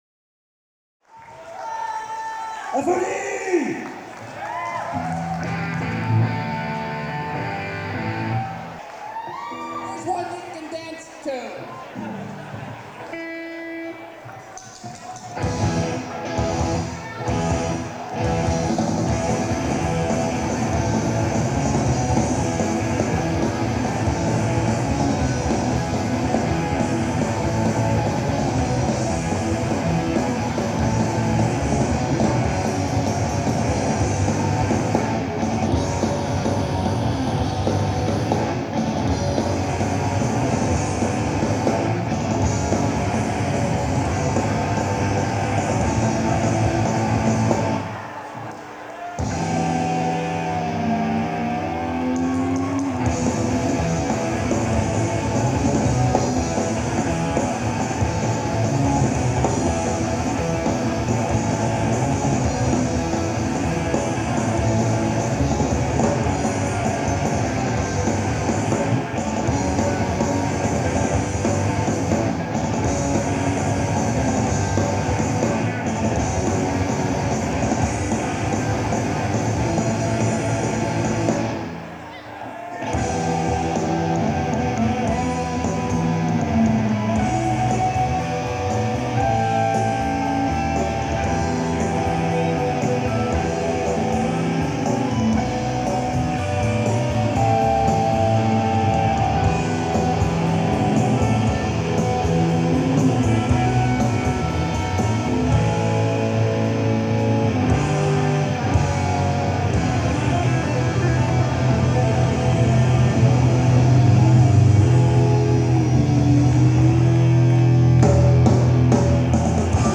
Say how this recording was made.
Live Tapes Trocadero 05-12-97 (Reunion show with Ruin & FOD)